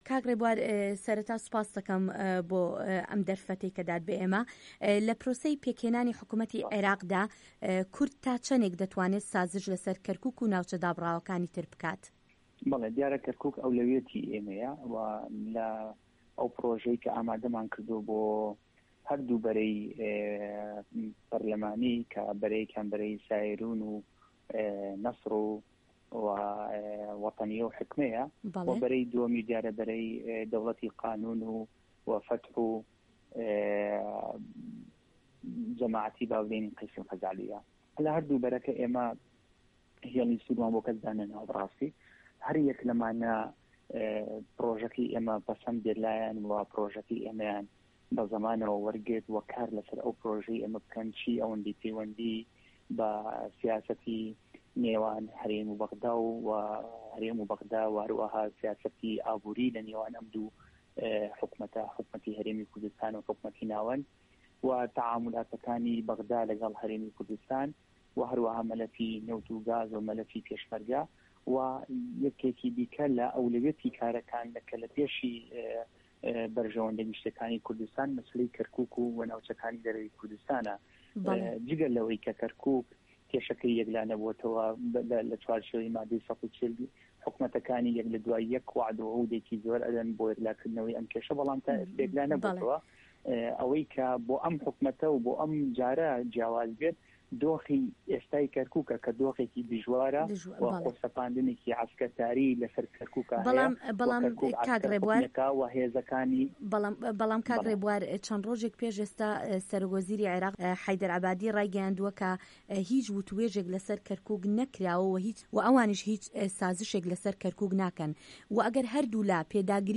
هه‌رێمه‌ کوردیـیه‌کان - گفتوگۆکان
ڕێبوار تەنها ئەندامی پەرلەمانی عێراق نوێنەری کەرکوک لە گفتوگۆیەکیدا لەگەڵ دەنگی ئەمەریکا گوتی کەرکوک سەردەستەی کارەکانیانە و لە پرۆژەکەی کە ئامادەشیان کردووە بۆ بەشداری کردن لە هەر هاوپەیمانێتیەک ئەمەیان بە ڕوونی خستۆتە ڕوو.